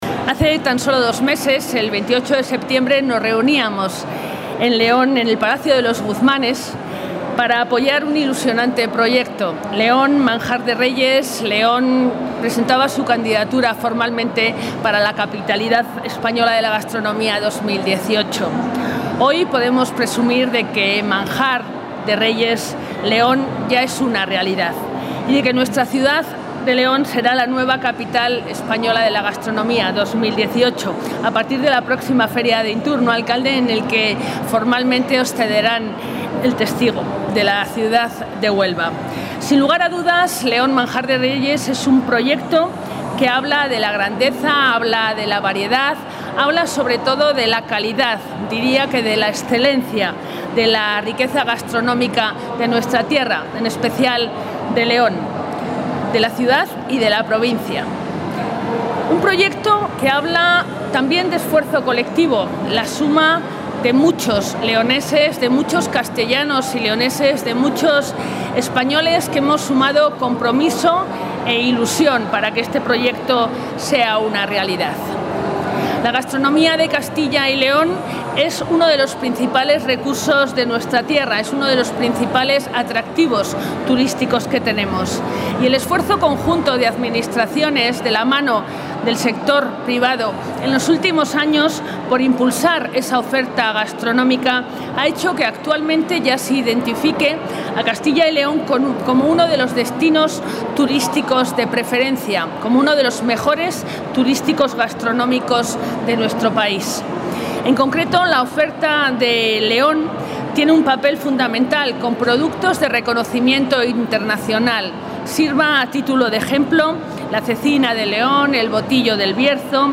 La consejera de Cultura y Turismo, María Josefa García Cirac, y el alcalde de León, Antonio Silván, han presentado hoy,...
Intervención de la consejera de Cultura y Turismo.